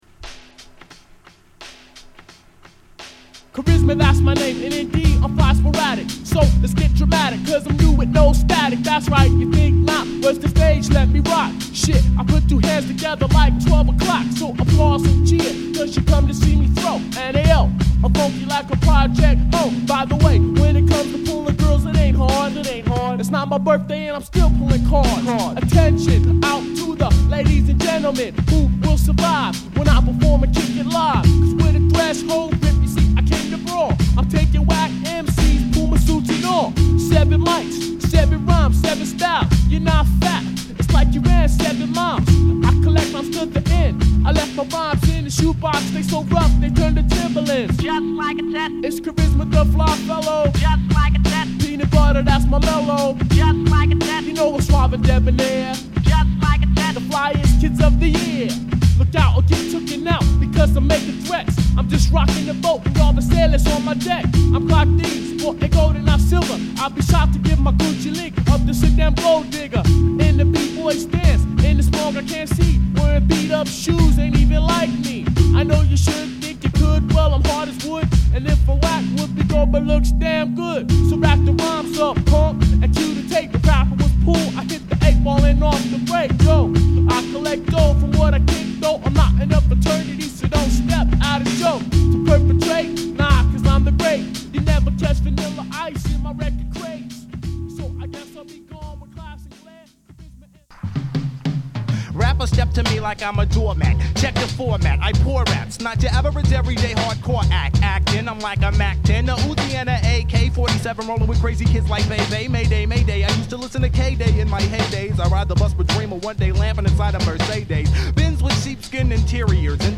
94年リリースのアングラHip Hopコンピレーション。